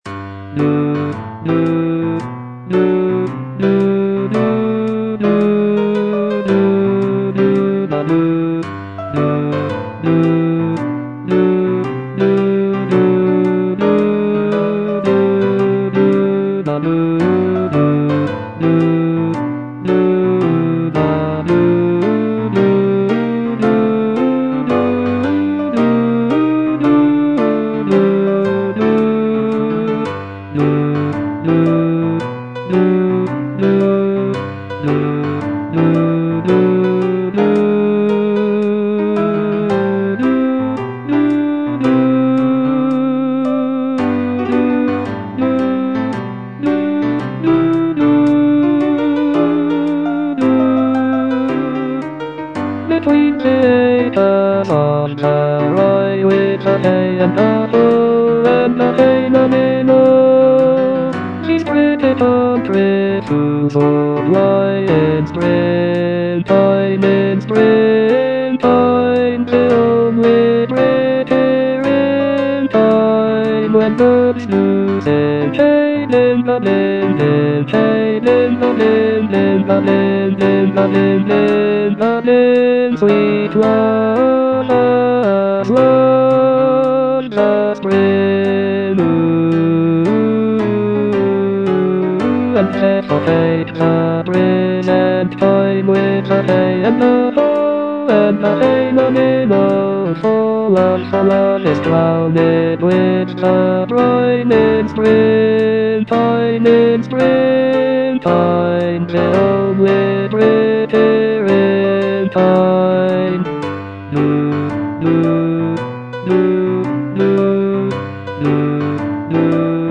Baritone (Voice with metronome)
choral work